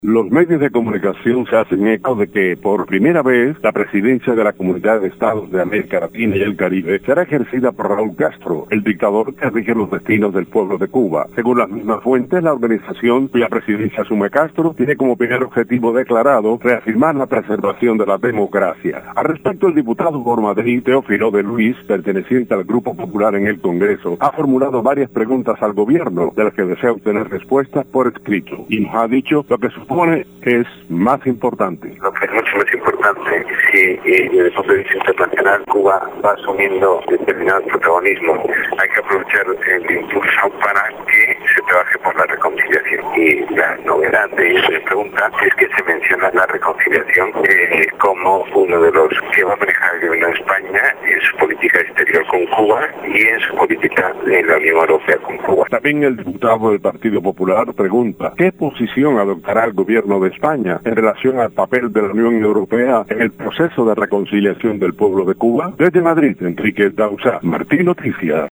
Los medios de prensa en España siguen analizando cómo será la política de la CELAC bajo la presidencia de Raúl Castro. En Madrid el diputado Teófilo de Luis dio declaraciones sobre el tema a Marti Noticias.